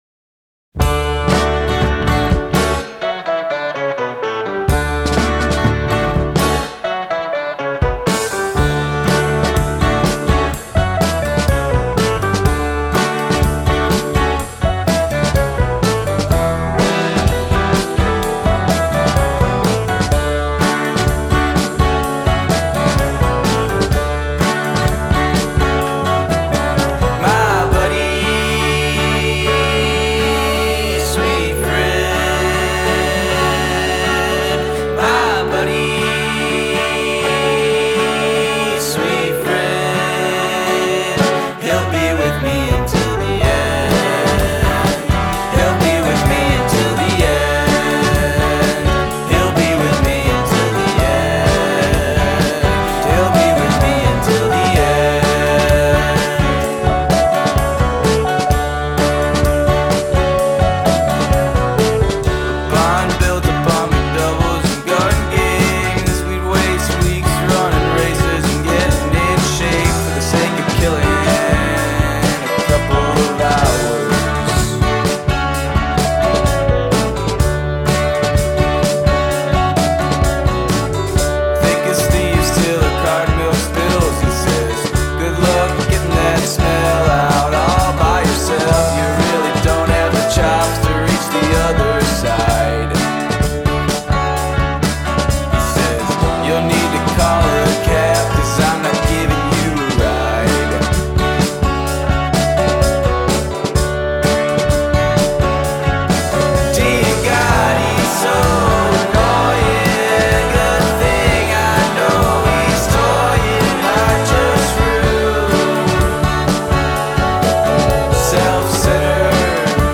jangly indie-band
is a freewheeling and feel-good single